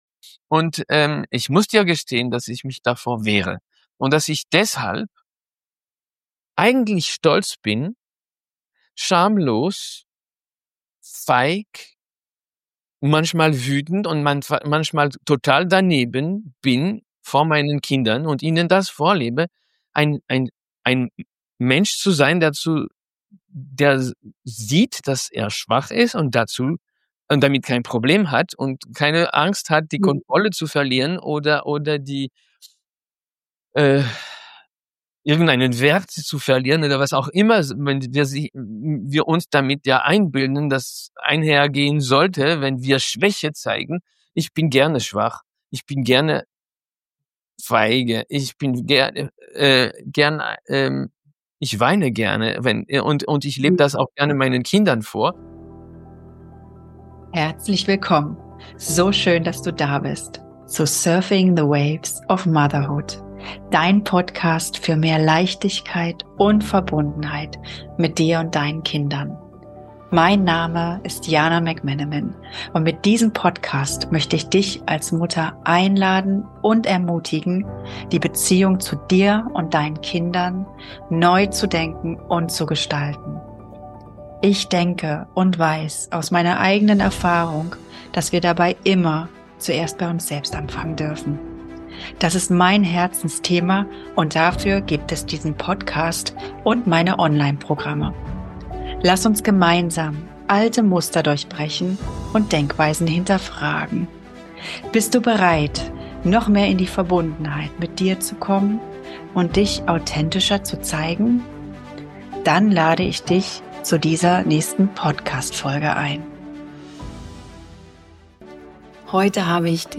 Ein Gespräch, das Druck herausnimmt und Mut macht, den eigenen Weg zu gehen.